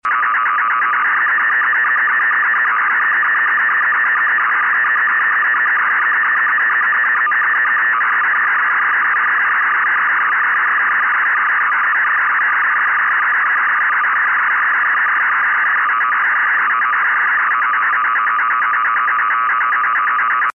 Audio file of MPT1327-like signal. Unable to decode either with Unitrunker nor Trunkview.